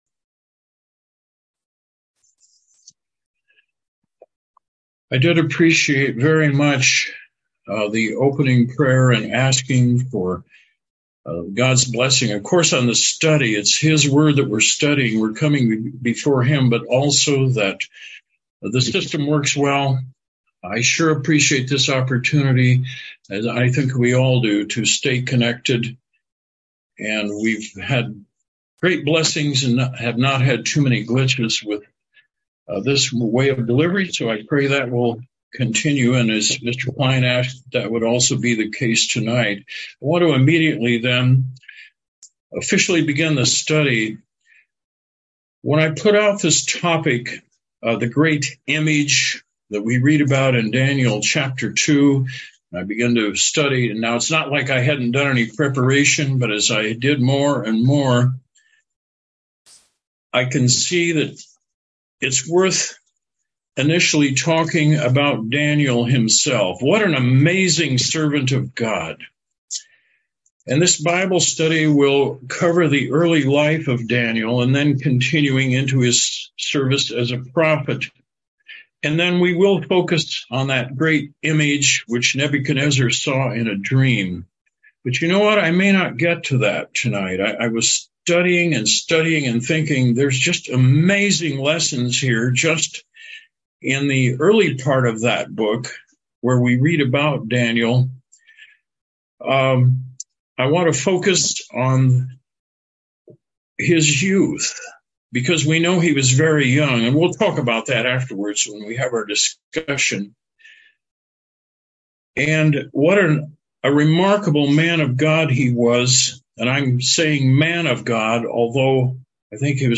This bible study covers some background to the book of Daniel and the events recorded beginning in Dan 1:1 through Dan 2:24.